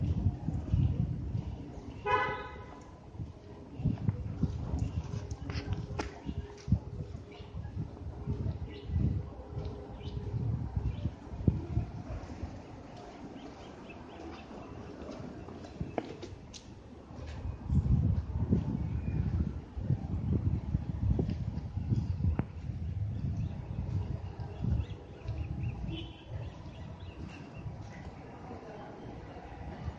描述：Sonido ambiente en zona residencial de Cali。